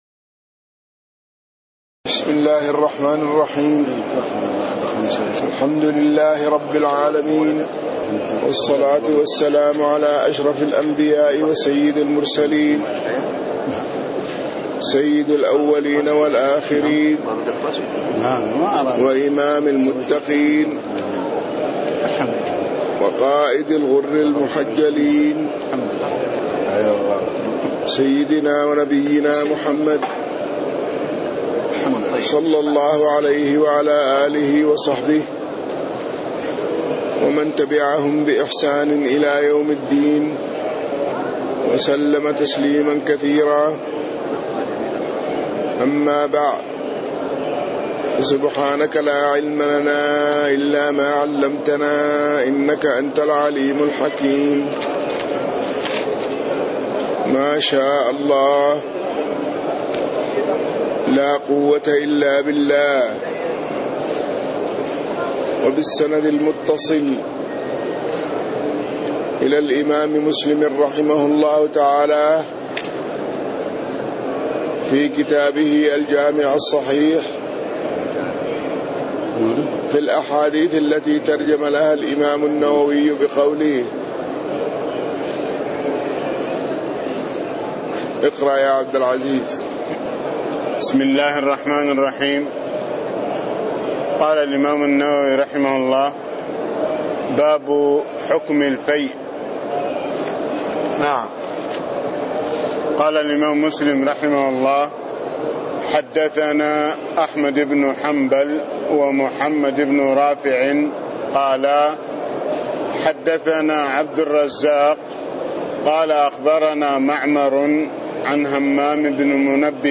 تاريخ النشر ١٢ رمضان ١٤٣٥ هـ المكان: المسجد النبوي الشيخ